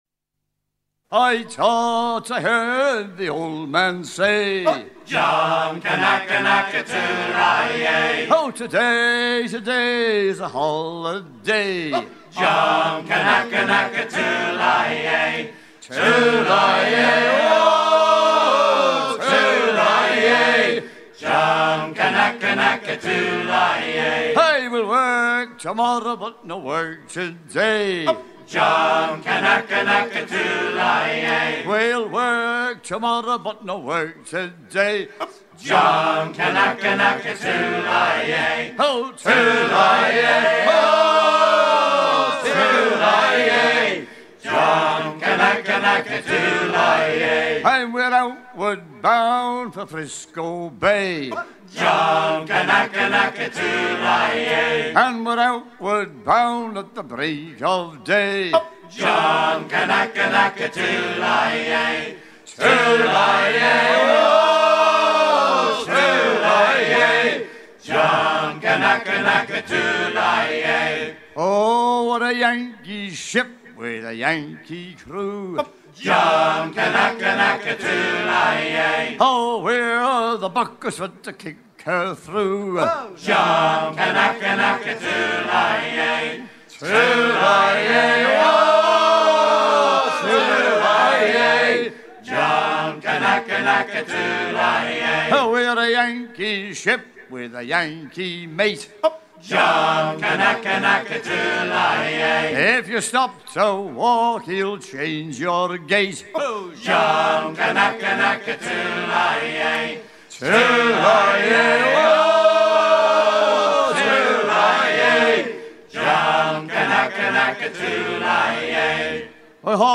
Le refain est d'origine polynésienne. enregistrement durant les fêtes de Douarnenez 88
Fonction d'après l'analyste gestuel : à hisser main sur main ;
Genre laisse